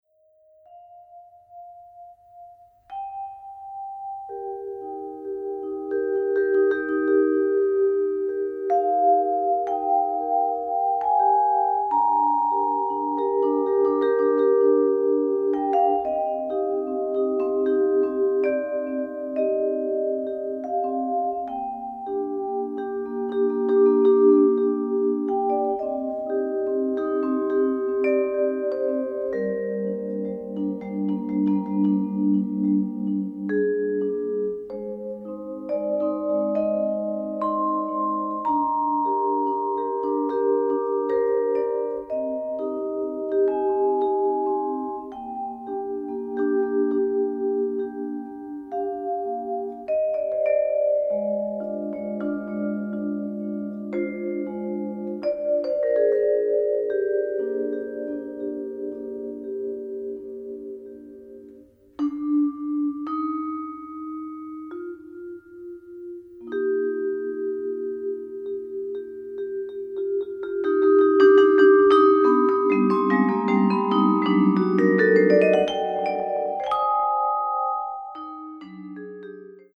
Australian, Classical